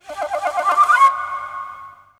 03-Flute 3.wav